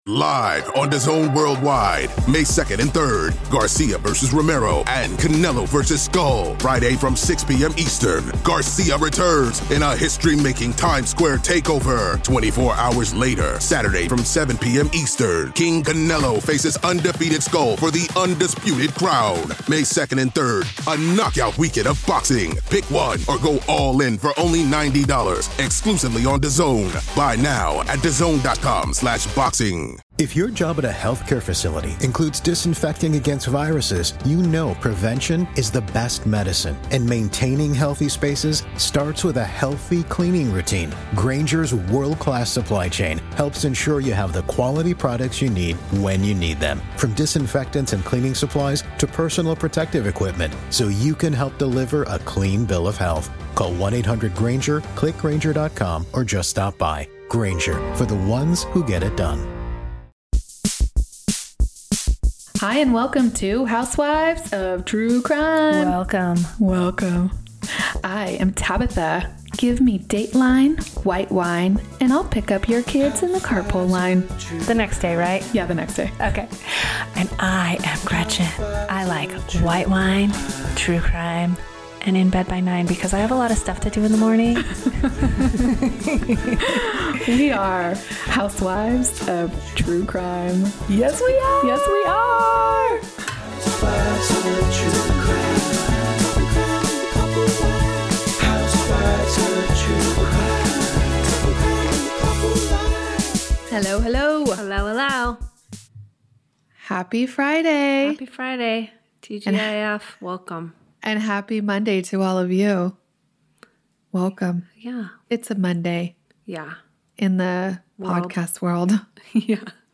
We have a guest housewife this week